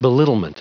Prononciation du mot belittlement en anglais (fichier audio)
Prononciation du mot : belittlement
belittlement.wav